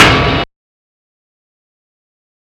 Snare (Kanye).wav